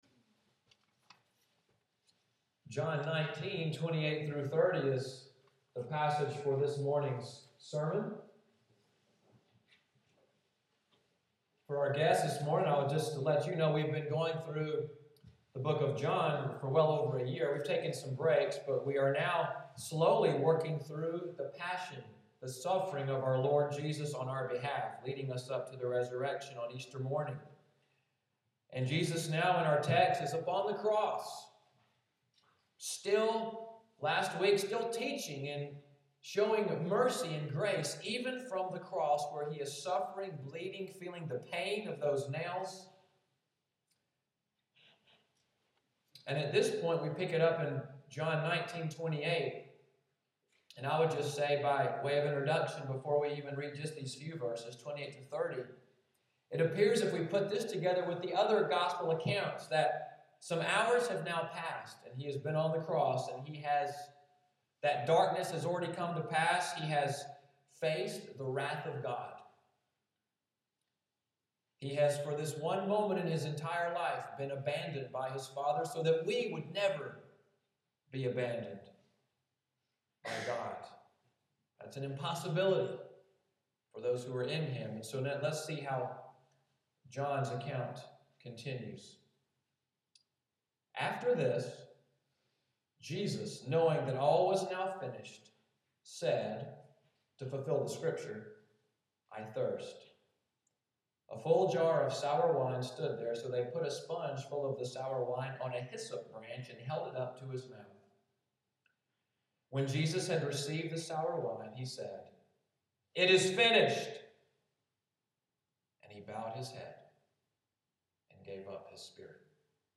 Audio from the sermon, “Mission Accomplished,” preached March 30, 2014